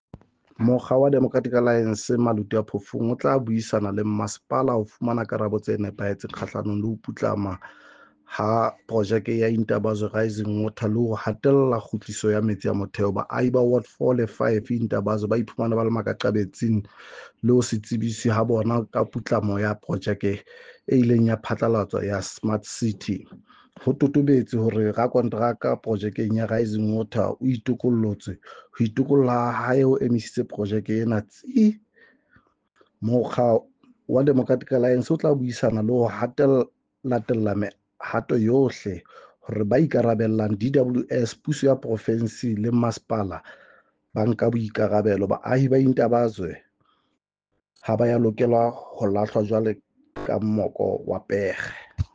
Sesotho soundbite by Cllr Paseka Mokoena.